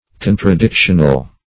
Search Result for " contradictional" : The Collaborative International Dictionary of English v.0.48: Contradictional \Con`tra*dic"tion*al\, a. Contradictory; inconsistent; opposing.